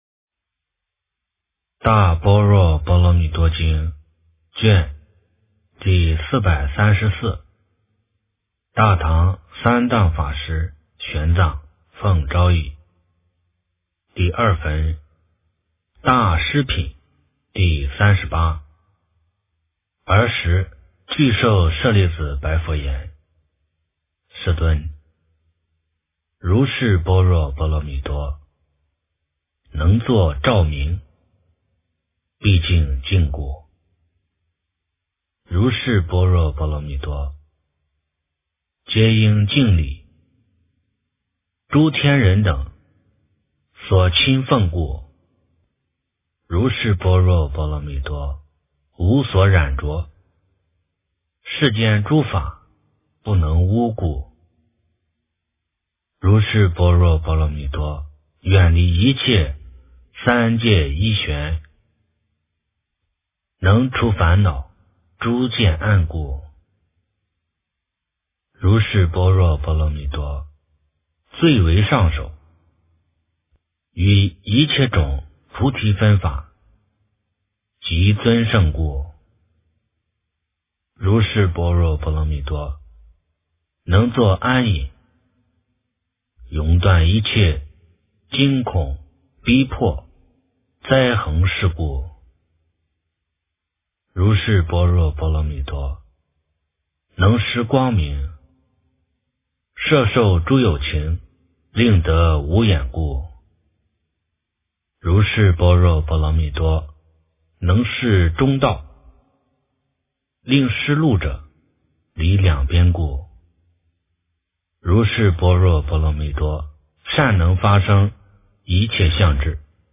大般若波罗蜜多经第434卷 - 诵经 - 云佛论坛